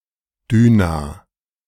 ^ Latvian: Daugava [ˈdˠaʊɡɐvˠɐ]; Latgalian: Daugova; Polish: Dźwina [dʑviˈna]; German: Düna [ˈdyːna]
De-Düna.ogg.mp3